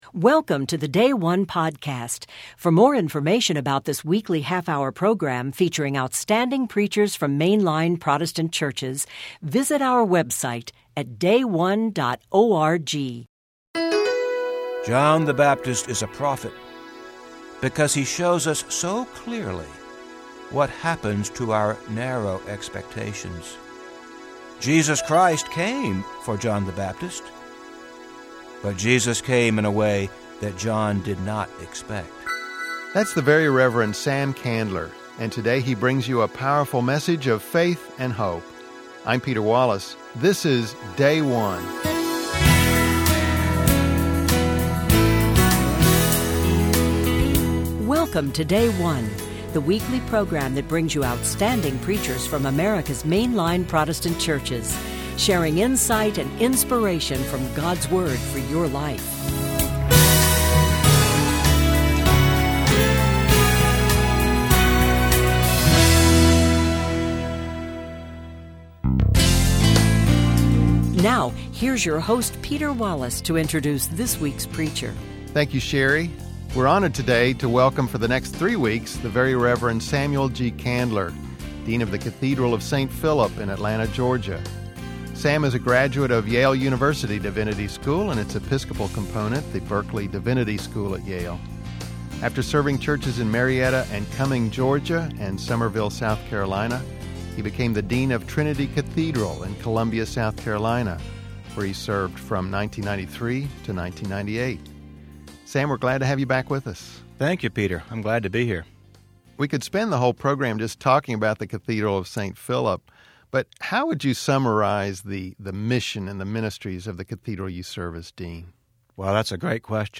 The Episcopal Church 3rd Sunday of Advent - Year A Matthew 11:2-11